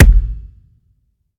normal-hitnormal.ogg